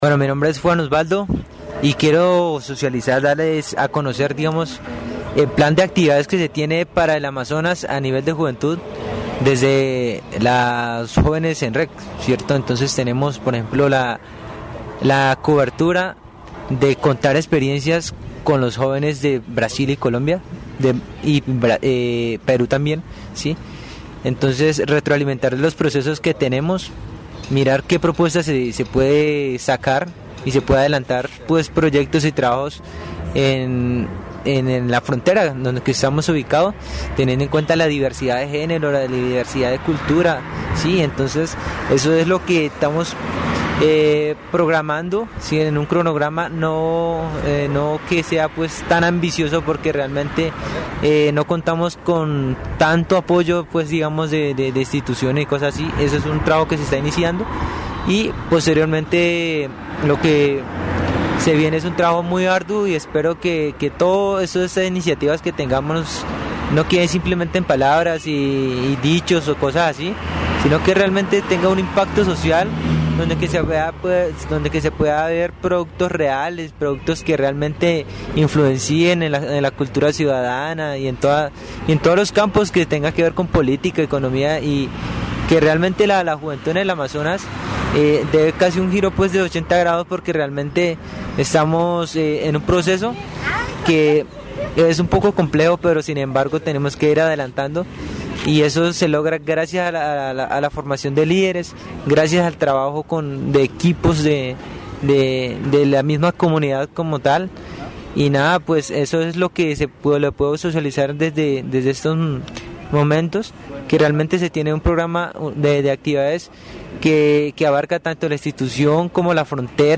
Programas de radio